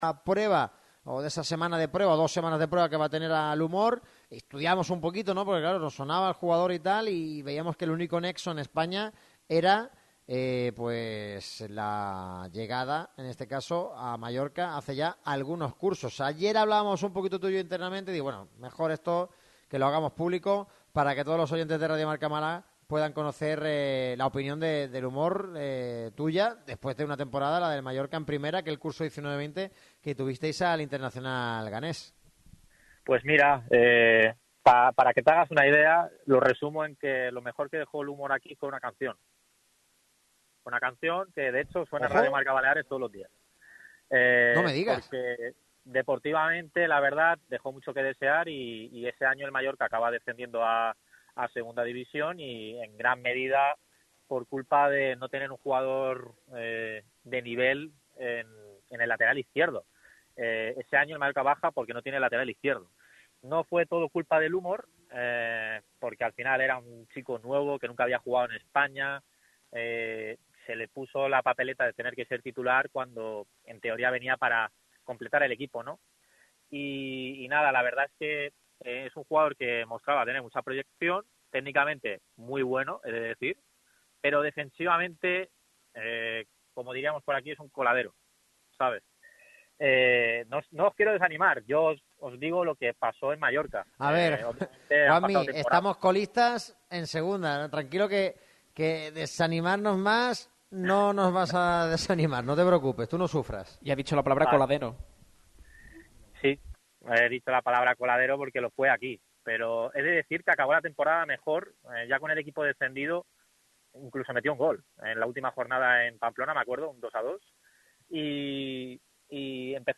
Un jugador pobre en defensa, al que la oportunidad en Primera le llegó demasiado pronto y que en la parcela ofensiva sí que destaca algo más.